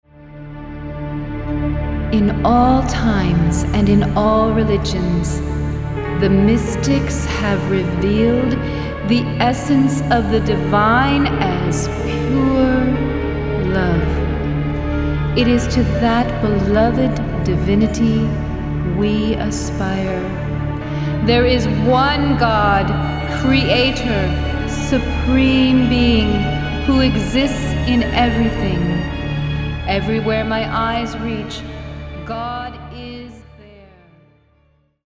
Recorded in Cue Studios, Vocals
Music